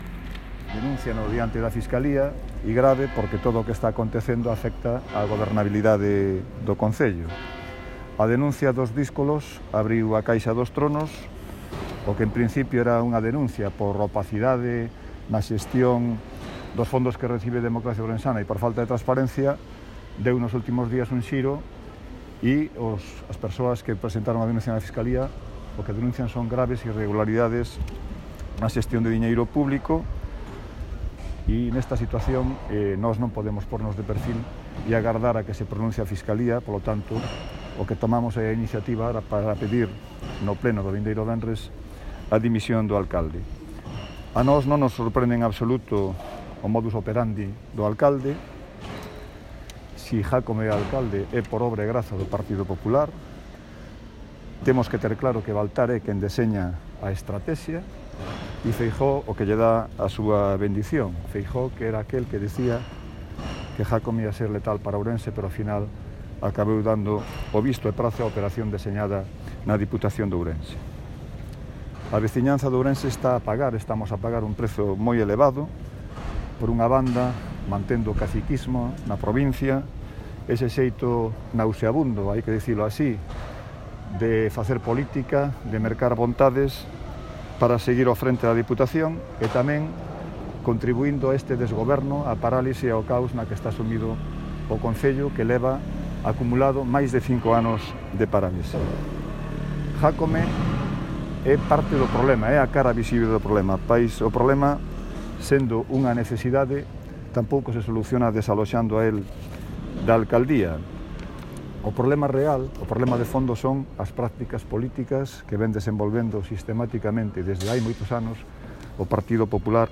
Nunha comparecencia diante dos medios esta mañá ás portas da casa do concello, os concelleiros Luis Seara e Rhut Reza avaliaron a situación da cidade e os motivos que os levan a presentar esta moción.